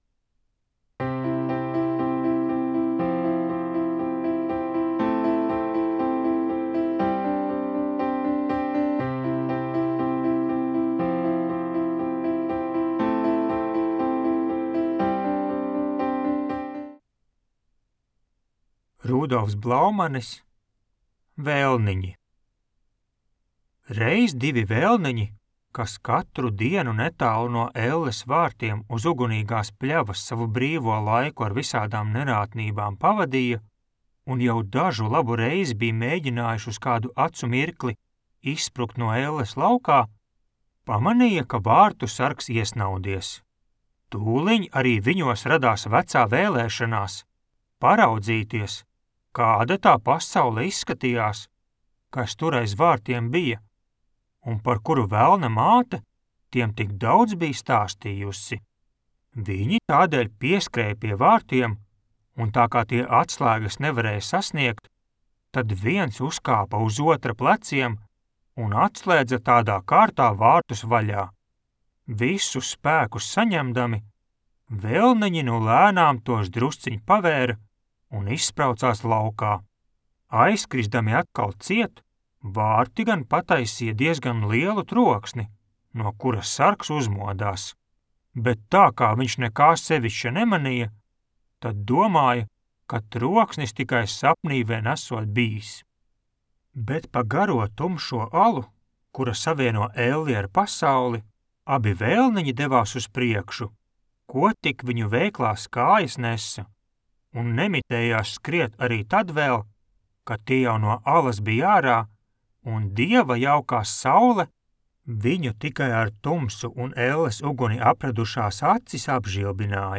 Mājaslapa piedāvā iepazīt latviešu literatūras klasiķu nozīmīgākos daiļdarbus audioierakstā.